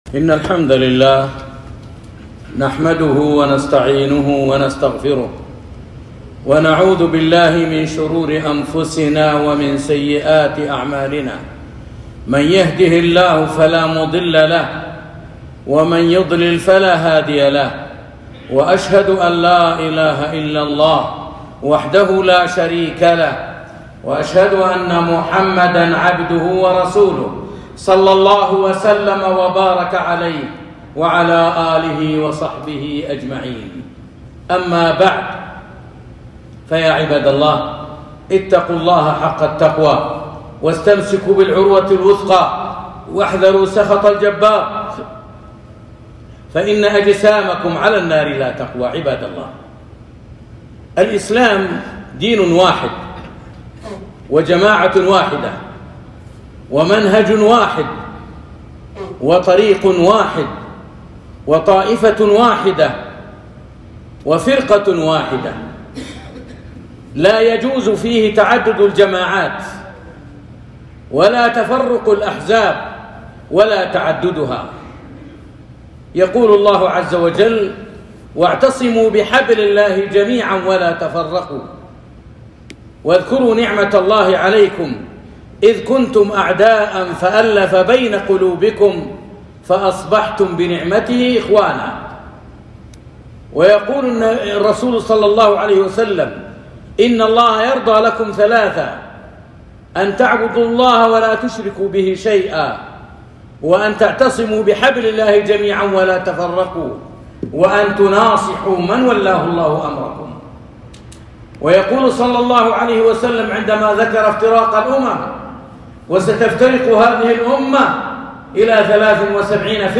خطبة - التحذير من جماعة التبليغ